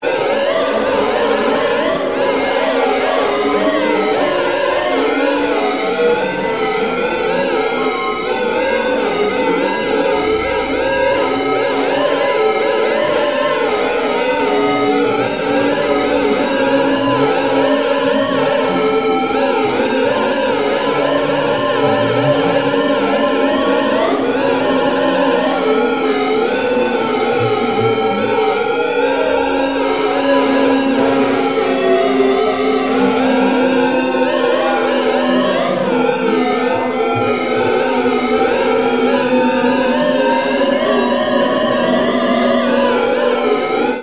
Solo guitar improvisations, variously layered
Gibson ES-335 guitar
I also used an e-bow and A/DA Flanger.